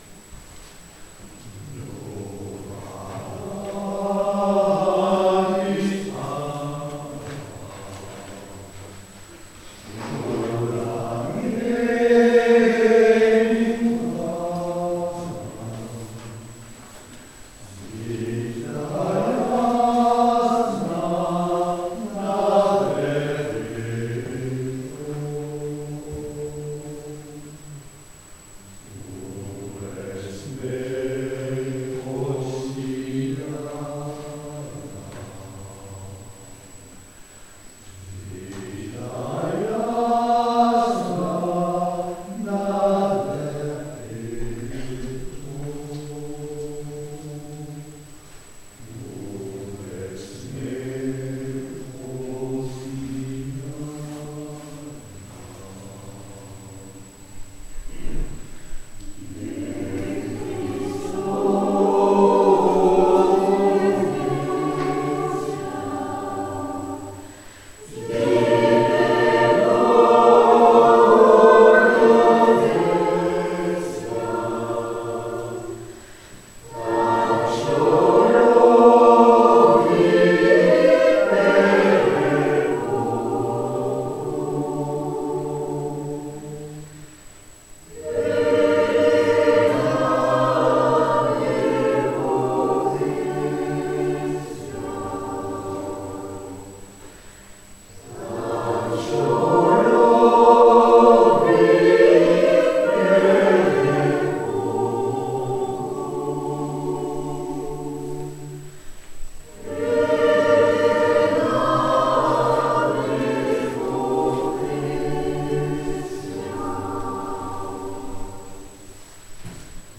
Pour ceux qui vous sont proposés à l'écoute, vous voudrez bien excuser les parasites et la qualité inégale des enregistrements, tous réalisés en public.
Nowa radist' stala (4.15 Mo) - Noël ukrainien : autre harmonisation Kyrylo Stecenko Ukraine XIXème